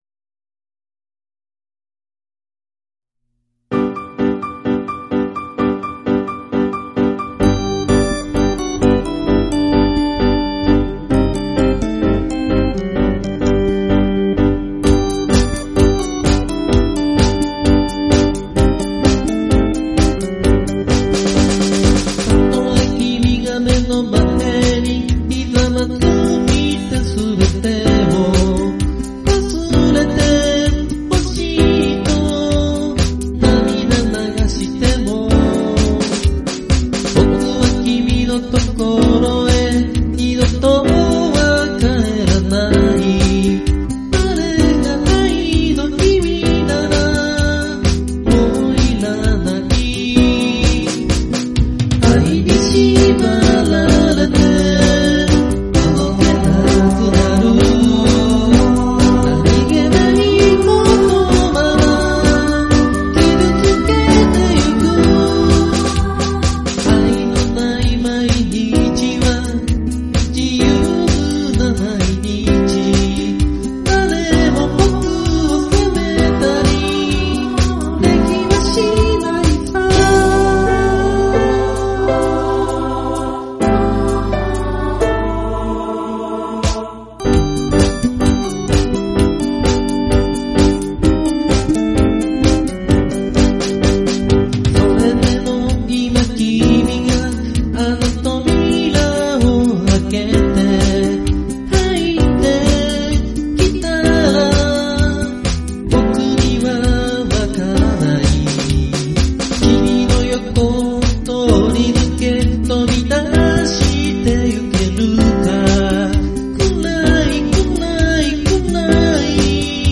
Vocal,Chorus,Bass,A.guitar,Keyboard,Drums
非常に歌うのがきつかったです。